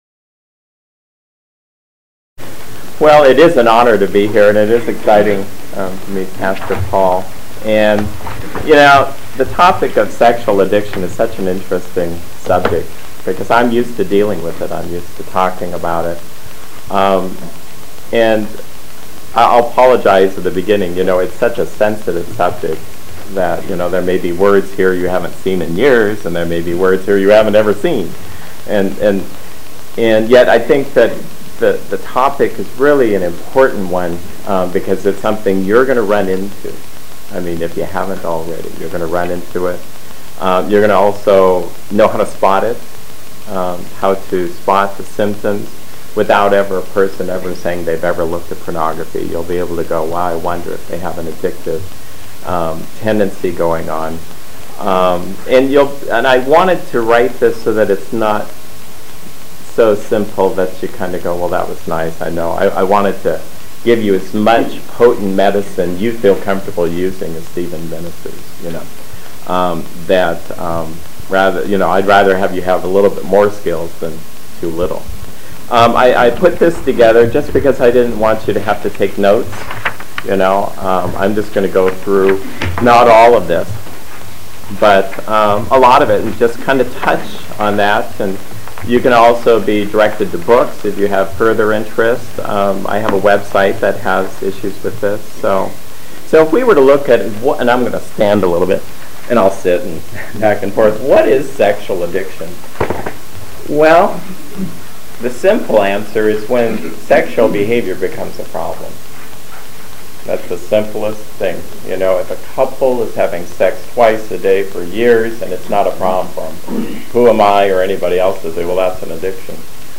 This talk was given to the Stephen's ministers at Northcreek Presbyterian Church.